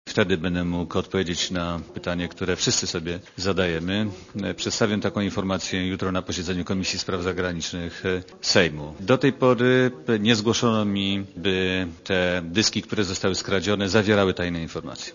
Mówi minister Cimoszewicz (59 KB)
Na konferencji prasowej w Warszawie Cimoszewicz poinformował też, że zgodnie z wyznaczonym przez niego terminem, do wczesnych godzin popołudniowych w środę ma zostać zakończony przegląd zawartości skopiowanych informacji na twardych dyskach komputerów, które znajdują się w MSZ.